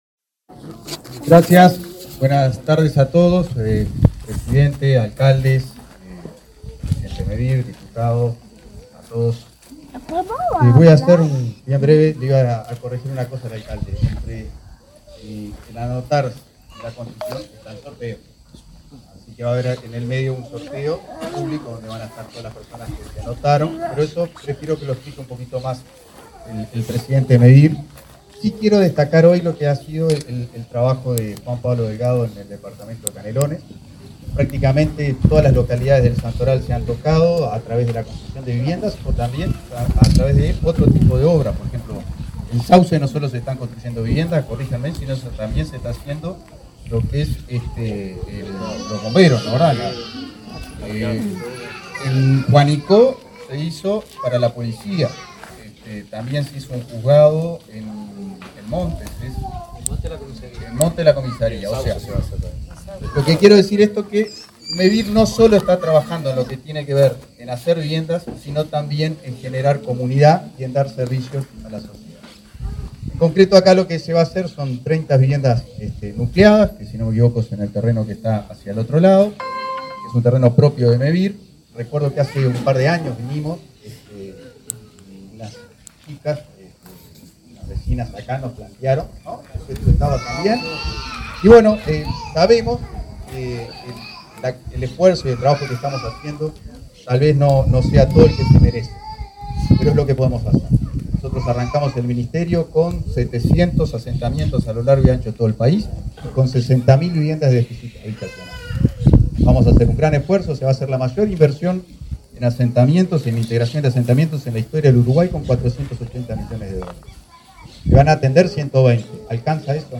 Palabras de autoridades en Canelones